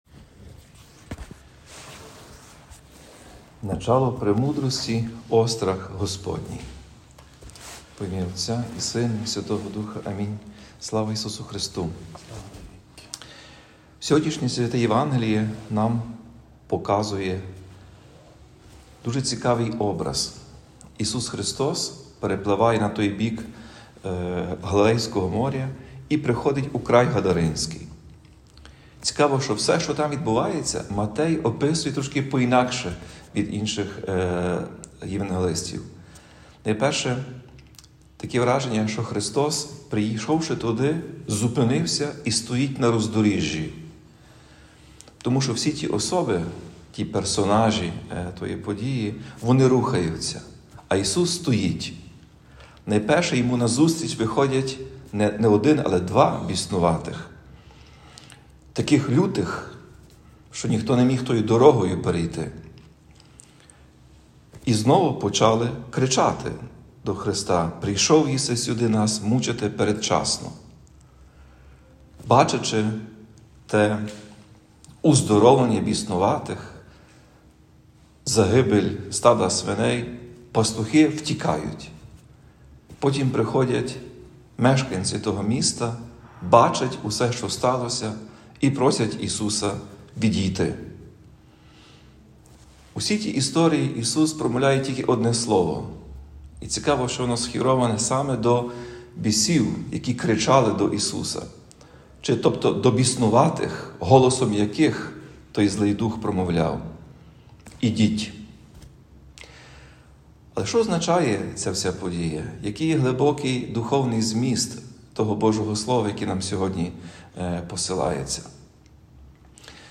Про це сказав Отець і Глава УГКЦ Блаженніший Святослав під час проповіді у 5-ту неділю після Зіслання Святого Духа.
Проповідь Блаженнішого Святослава